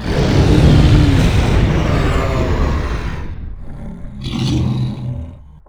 combat / creatures / dragon / he / die1.wav